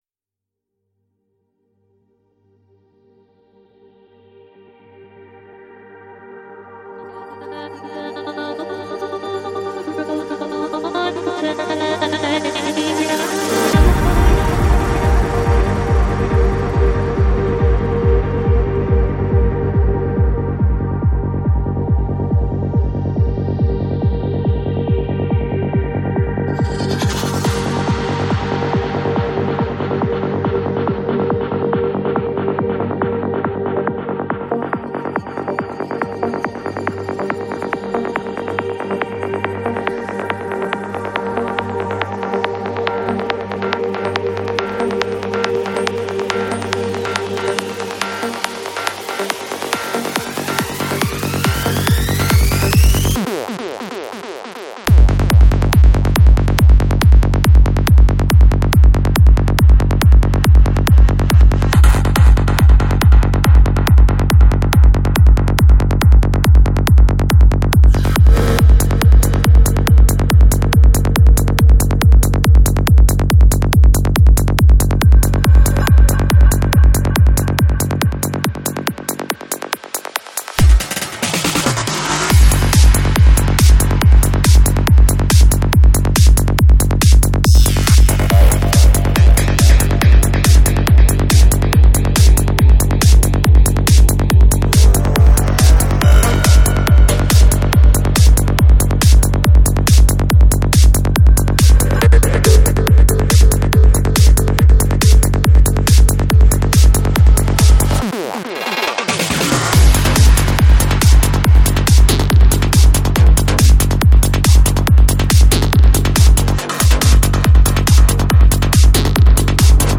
Жанр: Psychedelic Rock
Psy-Trance